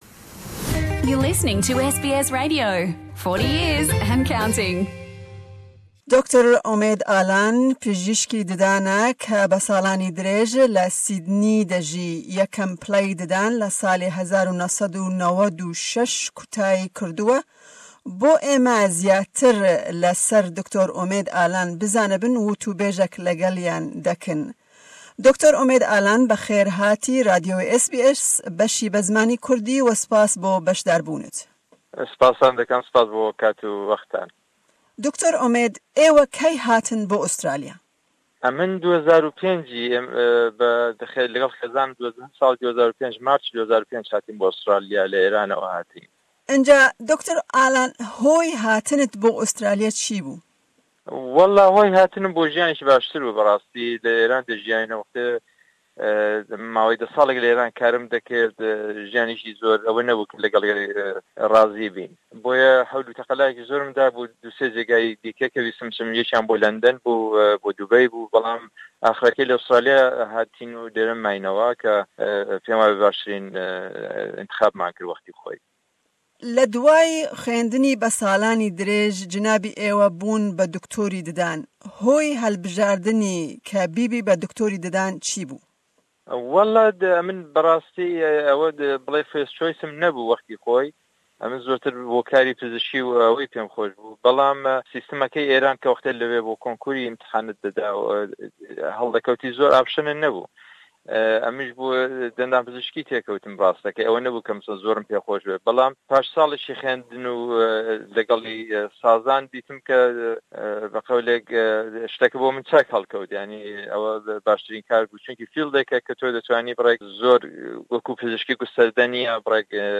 The interview is in Kurdish.